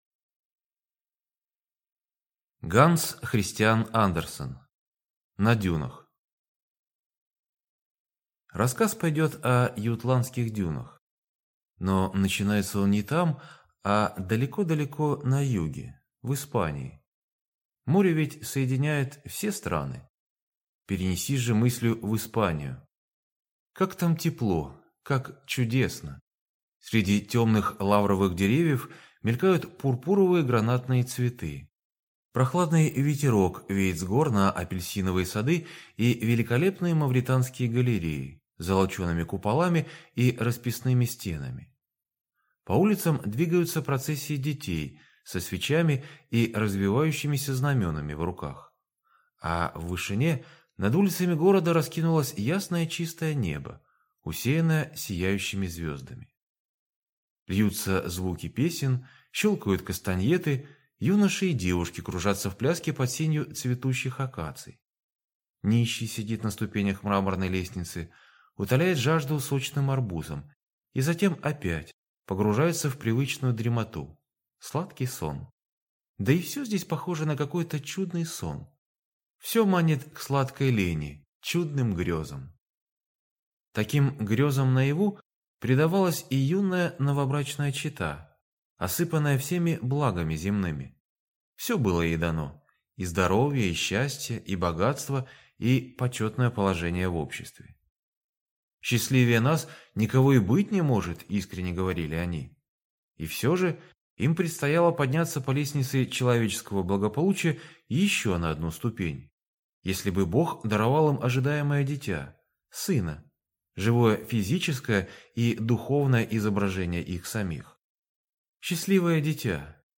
Аудиокнига На дюнах | Библиотека аудиокниг